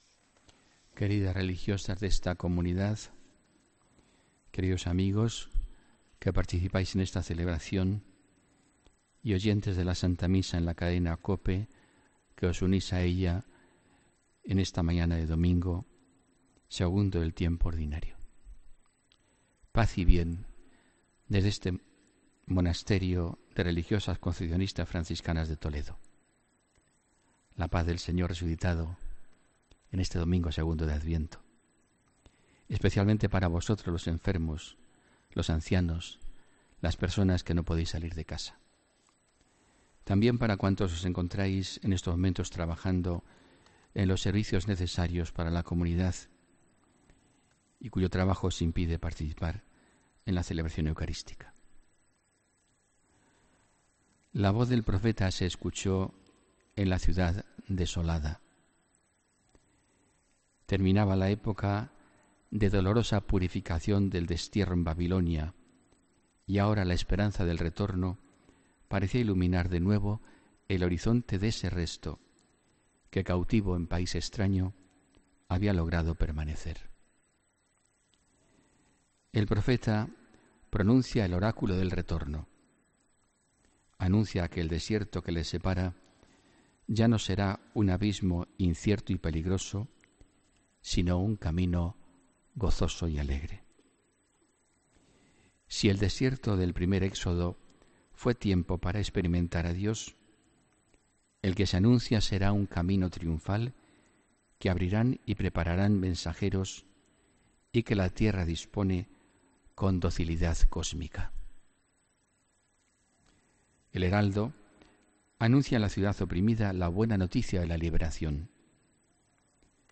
Homilía del domingo 10 de diciembre